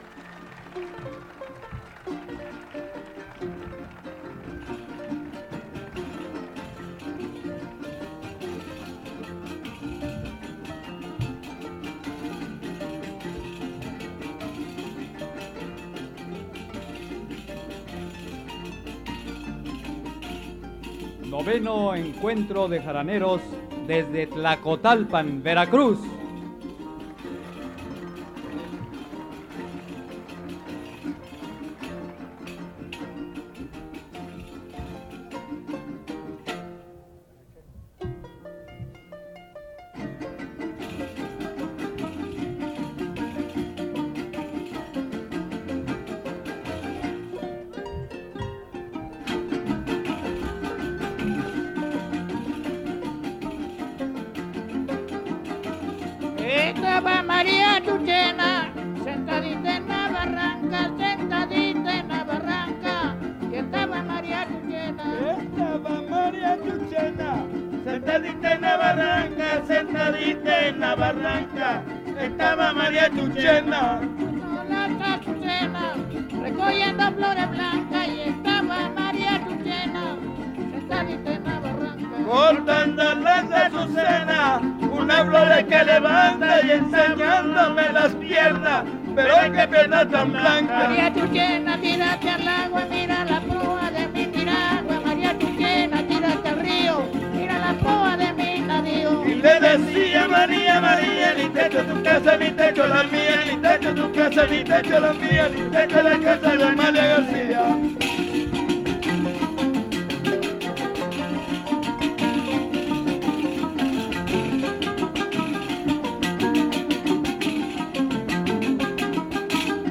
• Tlacotalpan (Grupo musical)
Noveno Encuentro de jaraneros